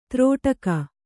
♪ trōṭaka